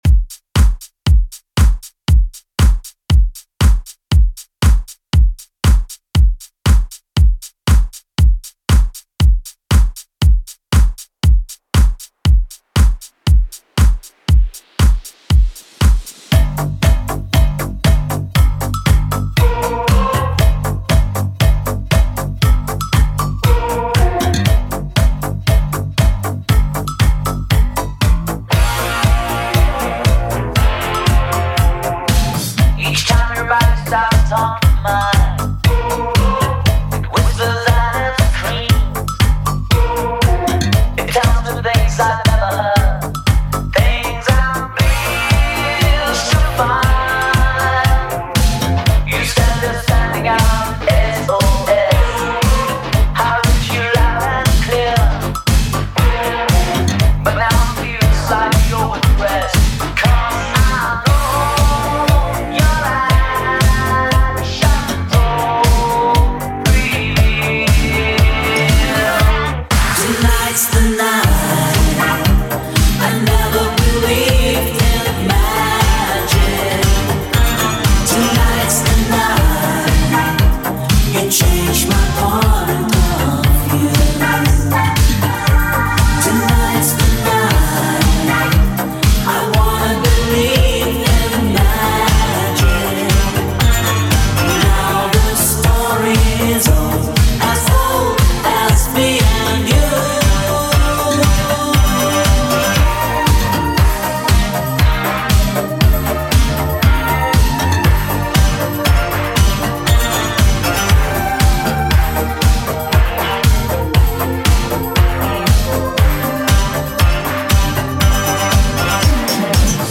Genre: 80's
BPM: 148